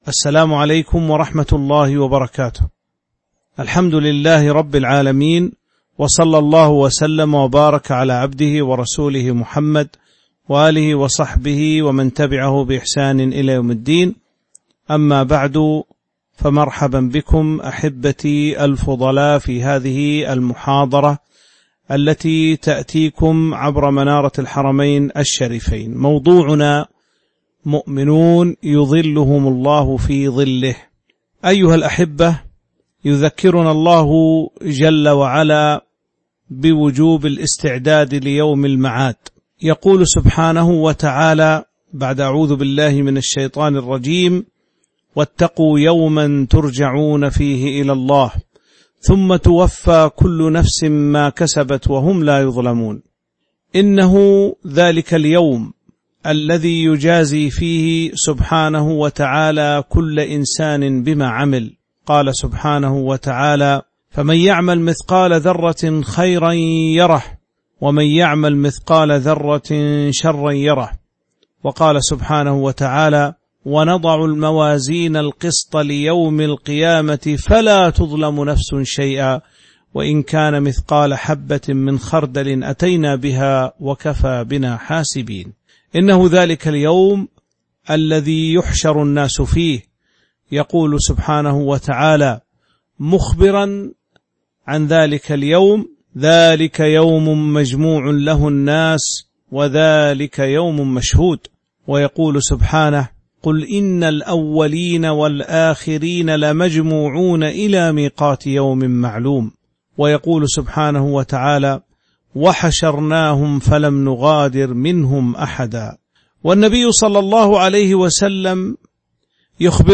تاريخ النشر ١٥ ذو الحجة ١٤٤٢ هـ المكان: المسجد النبوي الشيخ